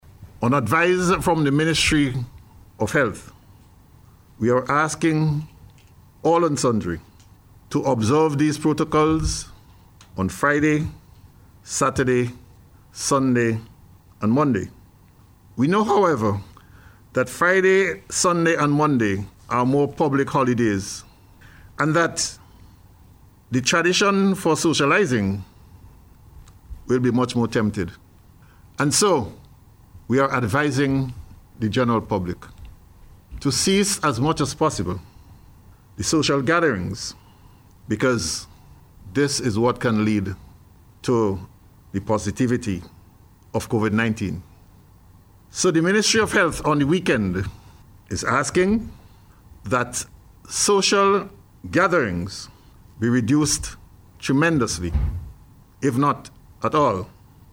Minister Daniel made the call while was delivering a national Address yesterday.